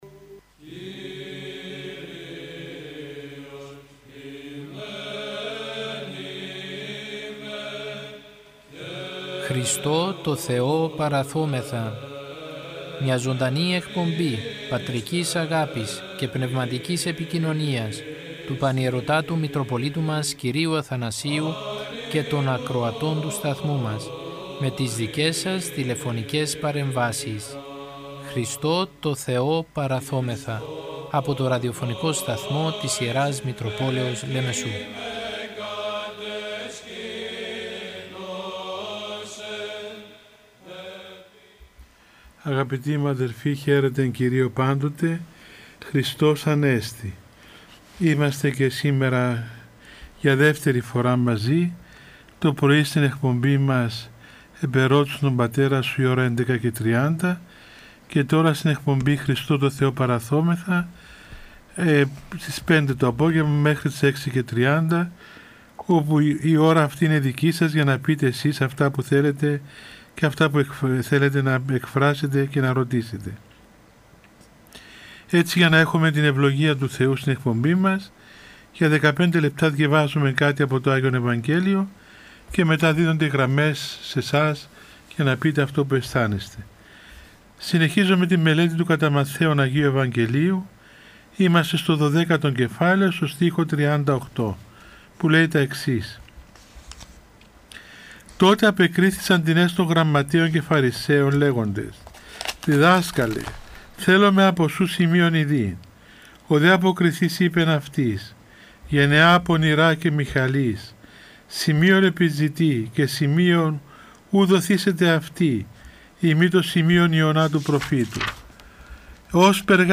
Η Ιερά Μητρόπολη Λεμεσού μετά από το διάταγμα που εξέδωσε η Κυβέρνηση για την αντιμετώπιση της πανδημίας του κορωνοϊού που περιορίζει τις μετακινήσεις και την προσέλευση των πιστών στους ναούς, θέλοντας να οικοδομήσει και να στηρίξει ψυχικά και πνευματικά όλους τους πιστούς παρουσιάζει καθημερινά ζωντανές εκπομπές με τον Πανιερώτατο Μητροπολίτη Λεμεσού κ. Αθανάσιο, με τίτλο «Χριστώ τω Θεώ παραθώμεθα».
Ο Πανιερώτατος απαντά στις τηλεφωνικές παρεμβάσεις των ακροατών του Ραδιοφωνικού Σταθμού της Ι. Μ. Λεμεσού και απευθύνει λόγο παρηγορητικό, παραμυθητικό και ποιμαντικό.